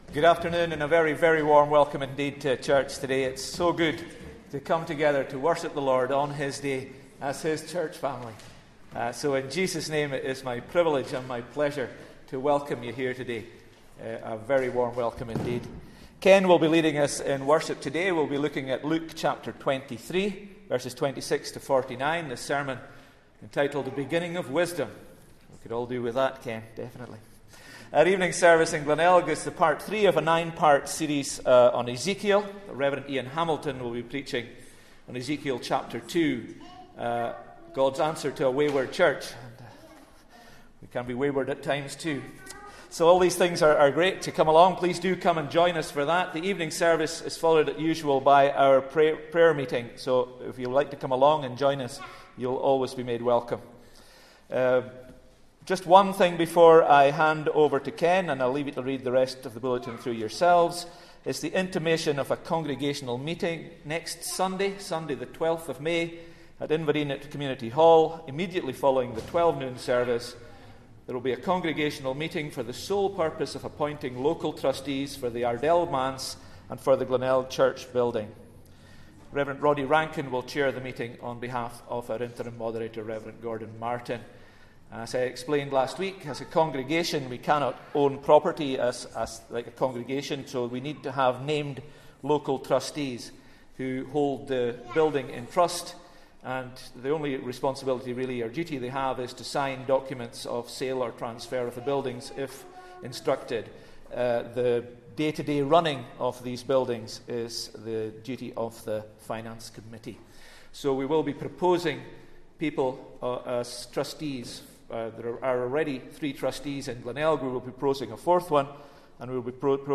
Sunday-Service-5th-May-2024.mp3